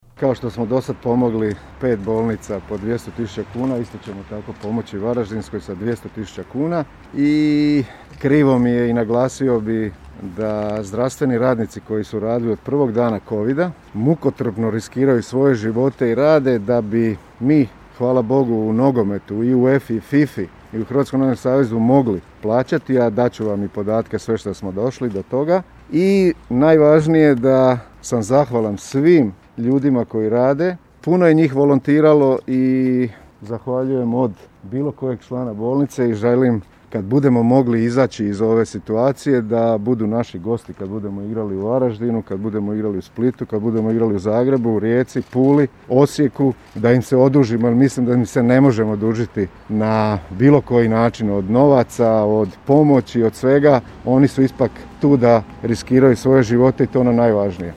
Govoreći o donaciji, Šuker je zahvalio zdravstvenim radnicima koji mukotrpno rade te riskiraju svoje živote.